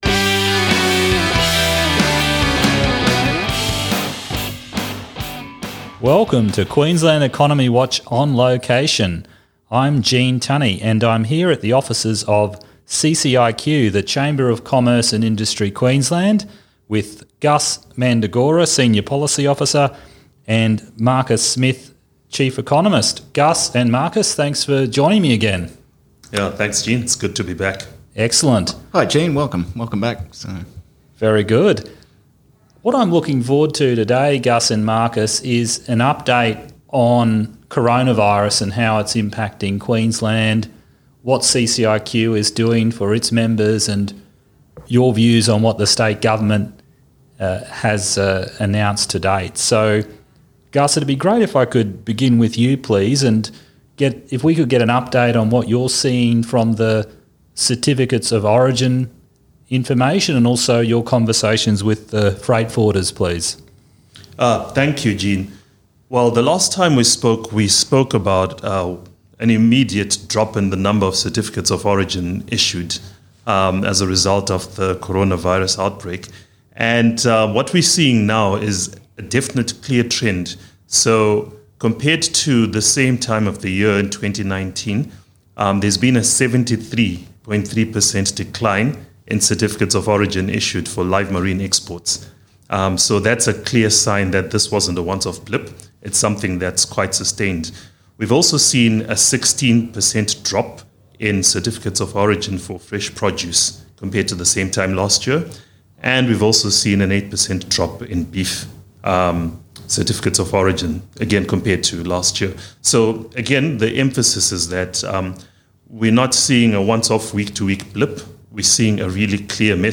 cciq-interview-2-march-20-for-qew.mp3